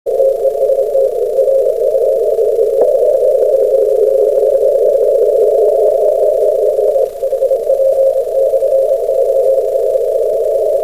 コンテスト中に録音したオーディオファイルを少し整理してみました。
またリグのLine-out端子にはサイドトーンが出てこないようで、小生が送信してる部分は無音状態になってしまってます。